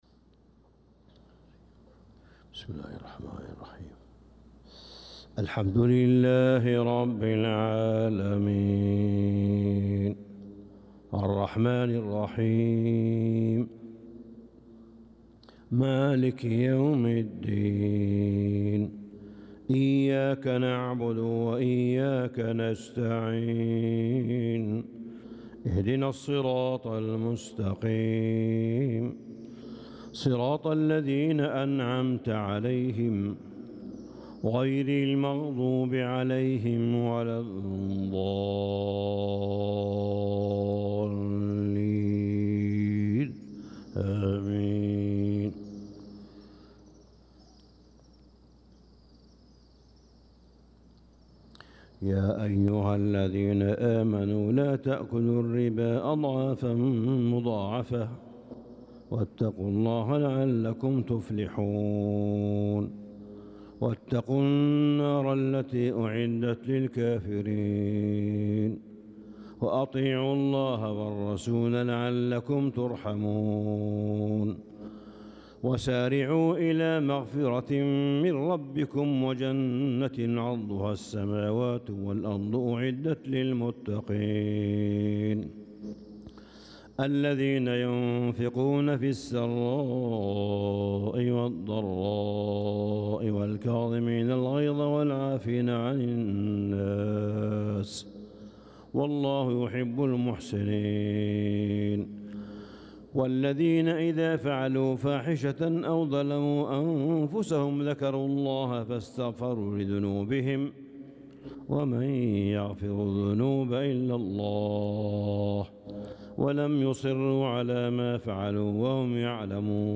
صلاة الفجر للقارئ صالح بن حميد 26 ذو القعدة 1445 هـ
تِلَاوَات الْحَرَمَيْن .